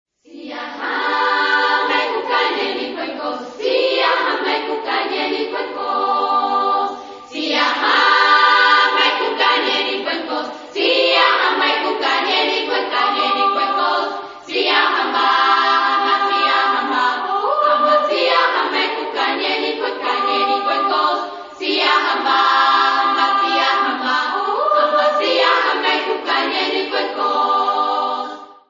African song
Genre-Style-Forme : Populaire ; Gospel ; Sacré
Caractère de la pièce : détendu ; heureux
Instrumentation : Percussions (ad libitum)
Instruments : Hochet (1) ; Tambour (1)
Tonalité : sol majeur